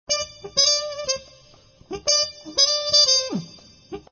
misc_jazzTrumpet00.mp3